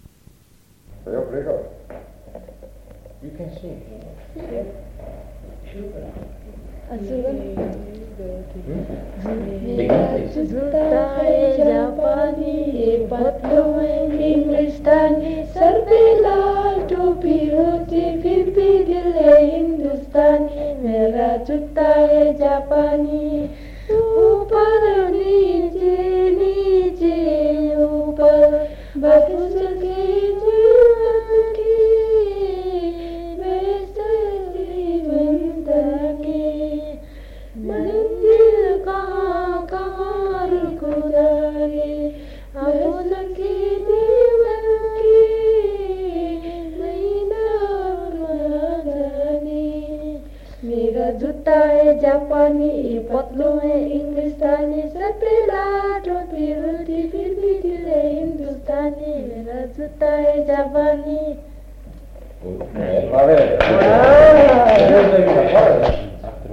A.7.2.19 - Mera joota hai japani (cantano le figlie di Tenzing Norgay in occasione del 6° Trento Film Festival, 1957)